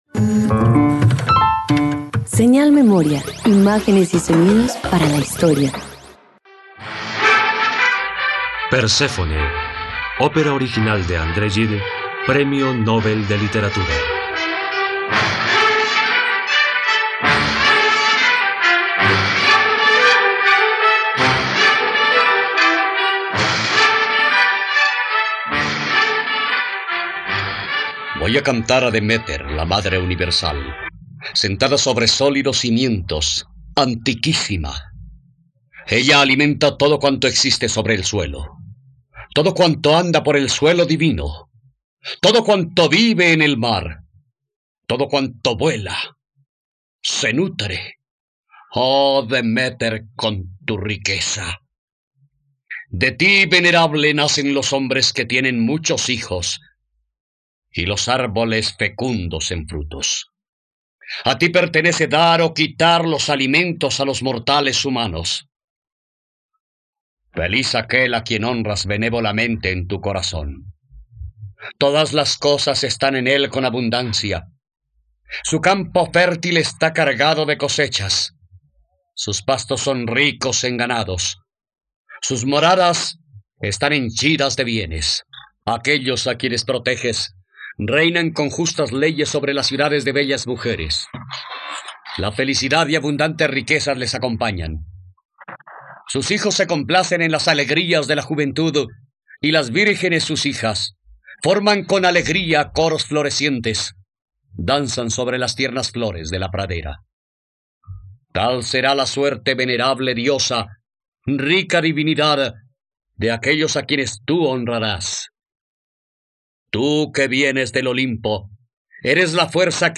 Perséfone - Radioteatro dominical | RTVCPlay
..Radioteatro. Escucha la historia de la diosa griega Perséfone, por la plataforma de streaming de todos los colombianos: RTVCPlay.